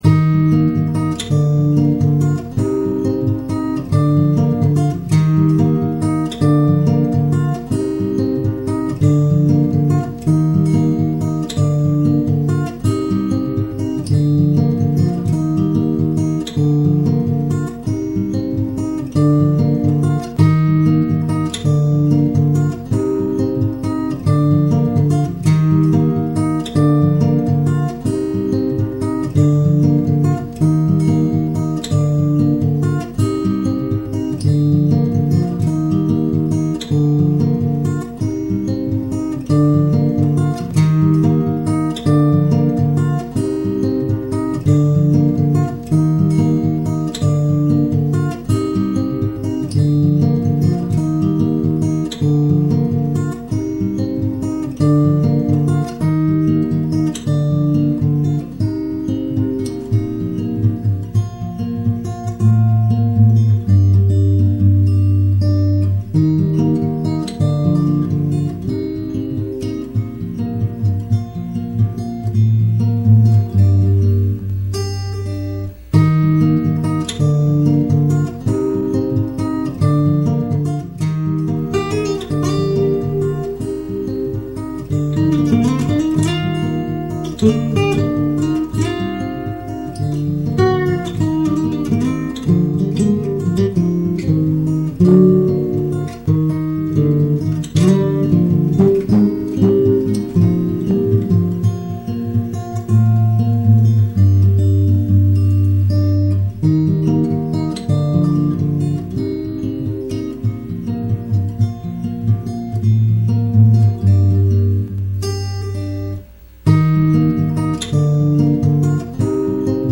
No Vocals Yet
This was recorded late ’98 in my room on campus.
It needs a voice, if you can sing let me know.